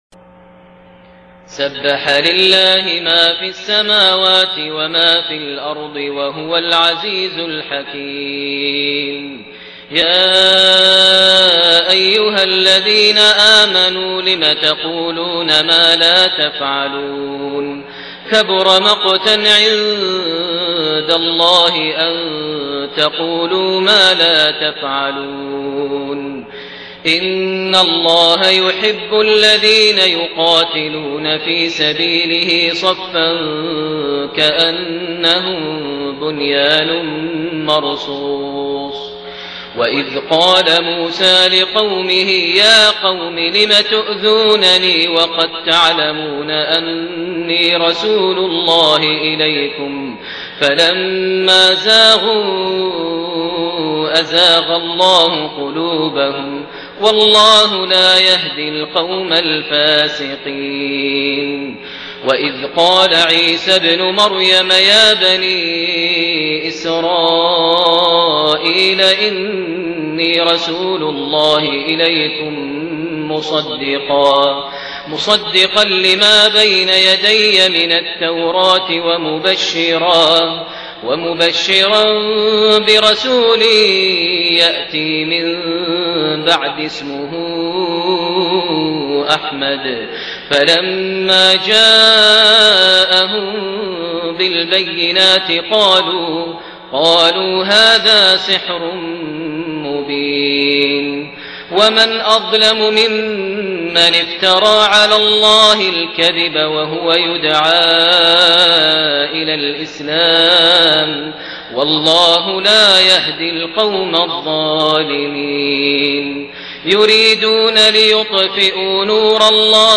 صلاة العشاء 11 محرم 1430هـ سورة الصف كاملة > 1430 🕋 > الفروض - تلاوات الحرمين